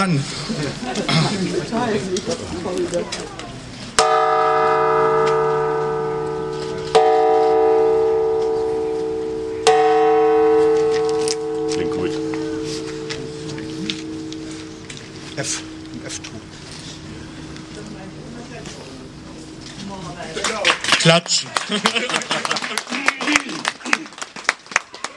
Anschlagen der Glocke